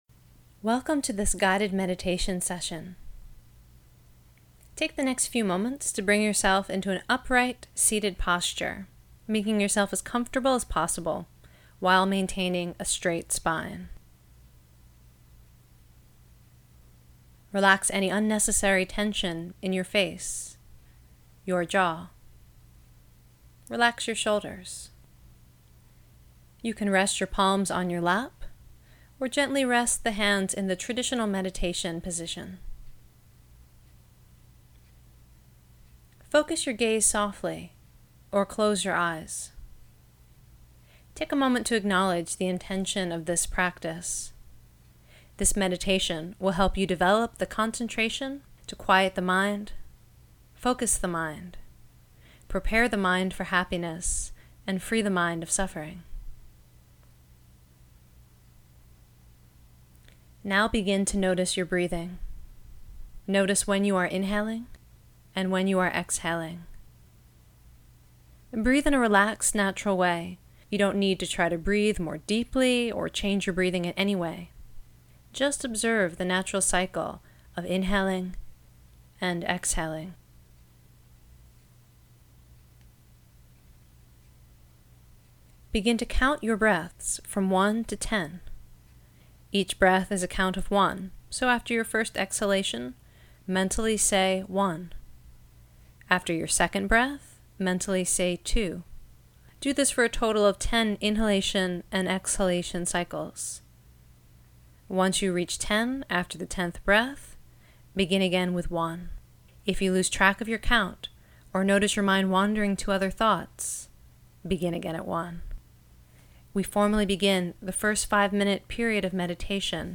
mindfulnessmed.mp3